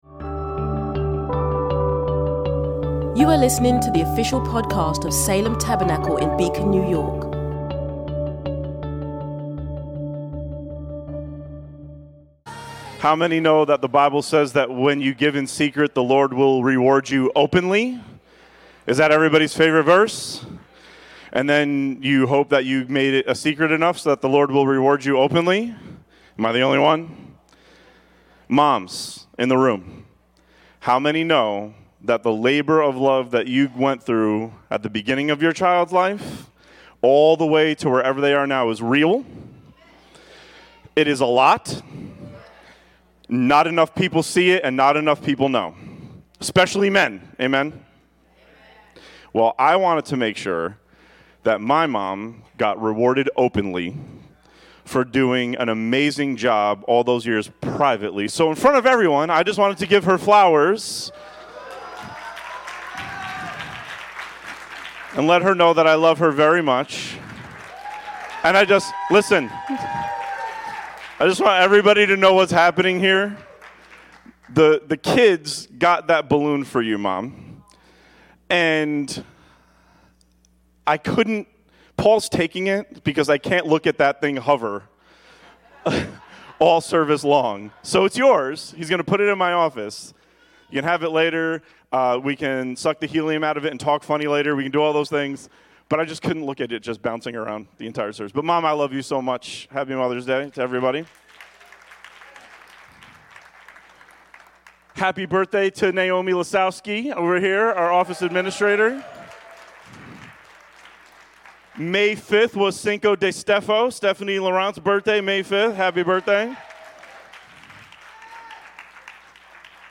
May 11, 2025 - Easter Eight Days a Week - 4 - Mother's Day - Non-Denominational, Convergent, Christian Community | Beacon, NY